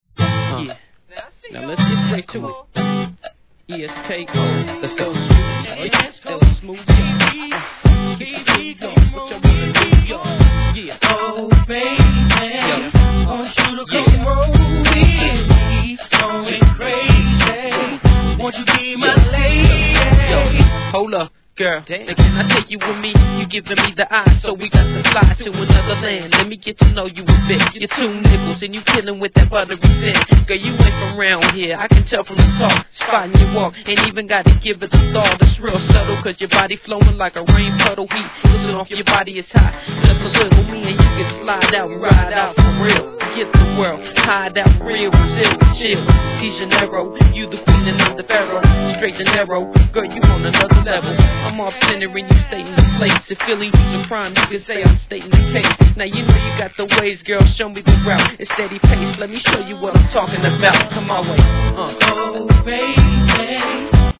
G-RAP/WEST COAST/SOUTH
アコースティックのシンプルなLOOPで仕上げたフロア使用、怒インディーR&B路線G!!